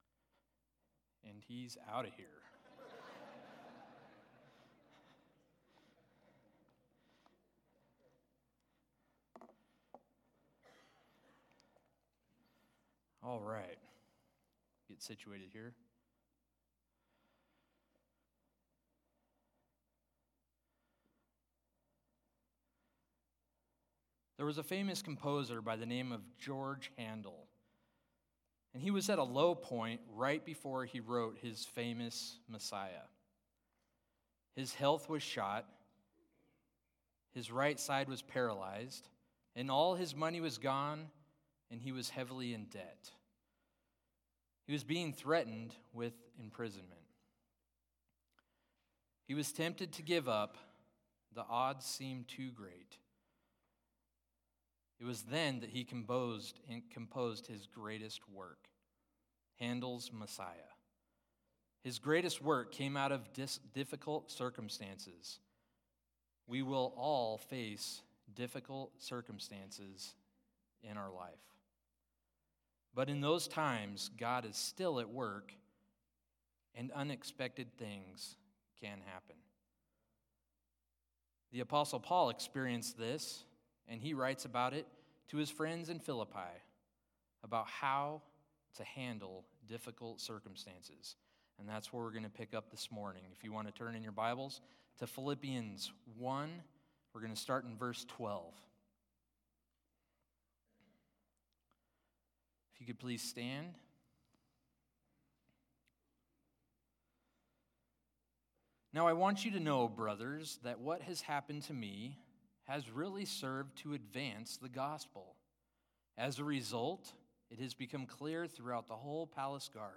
1.23.22-Sermon.mp3